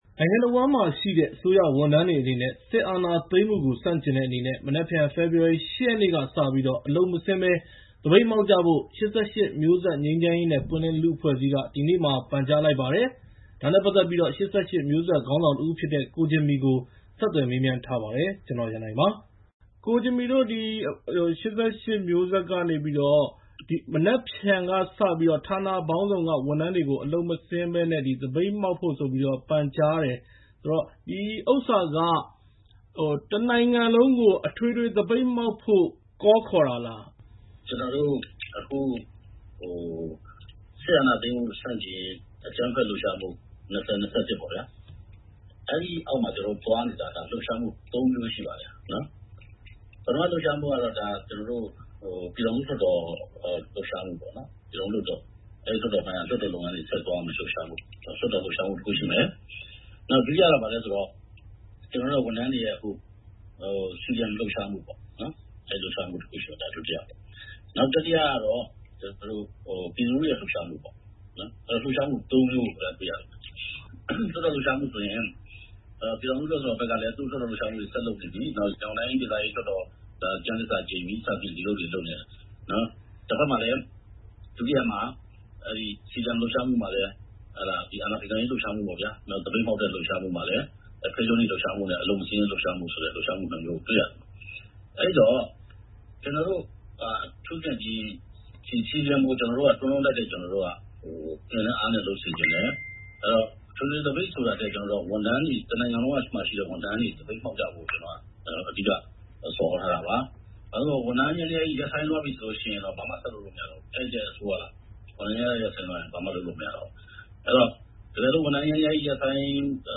၈၈ ငြိမ်း/ပွင့်ခေါင်းဆောင် ကိုဂျင်မီနဲ့ VOA ဆက်သွယ်မေးမြန်းချက်